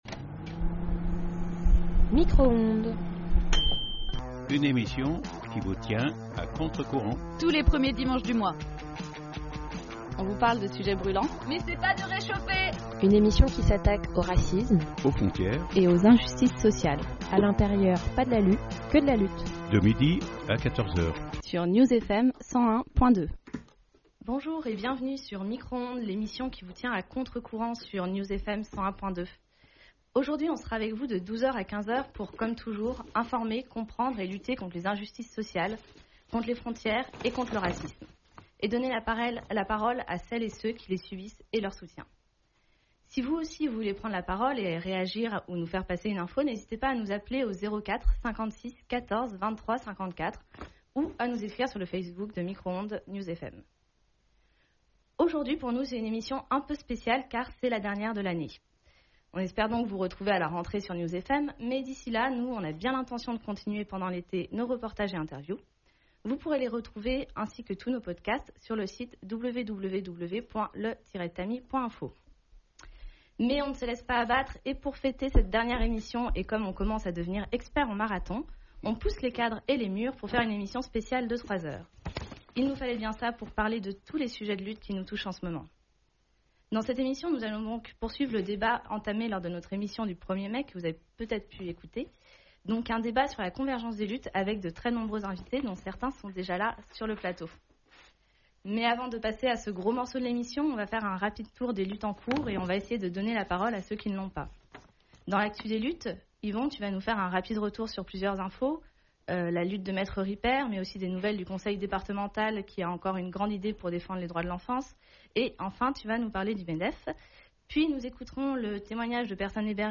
Micro-Ondes est une émission de radio diffusée tous les premiers dimanches du mois de 12h à 14h, sur New’s FM (101.2FM).